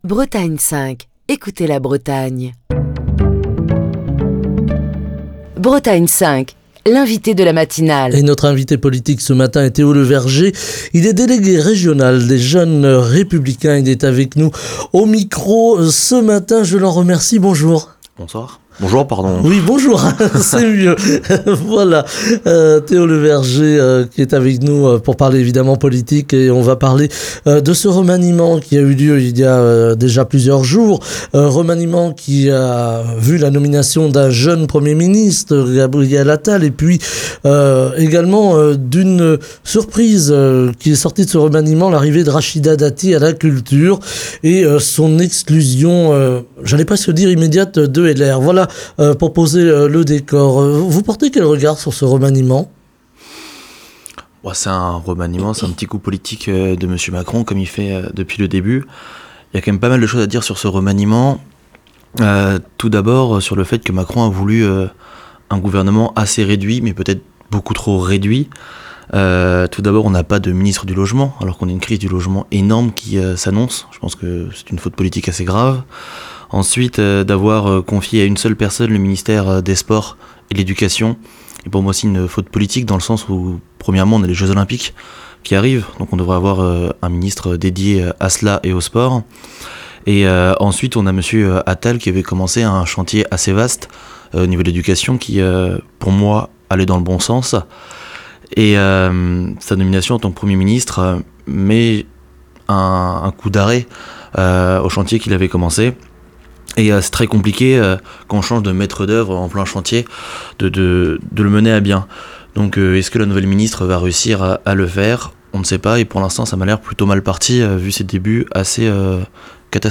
Au sommaire de l'interview politique de ce mardi : Retour sur le remaniement, l'arrivée de Rachida Dati au Ministère de la Culture et son exclusion de LR, la crise agricole, les européennes et la progression des intentions de vote pour le RN.